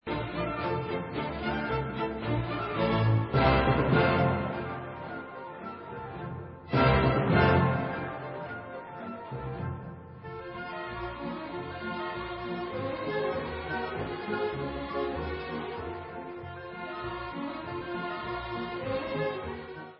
c moll (Allegro assai) /Skočná